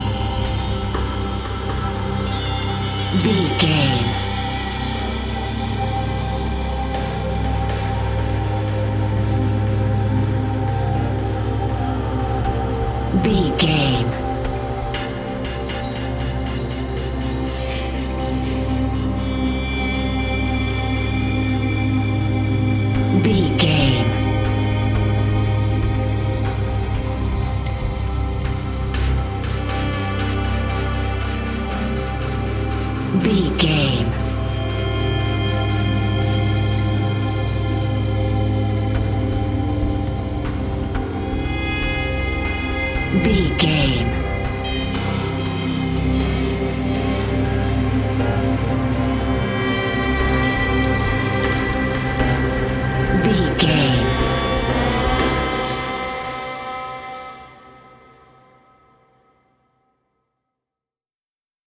Aeolian/Minor
synthesiser
percussion
tension
ominous
dark
suspense
haunting
creepy
spooky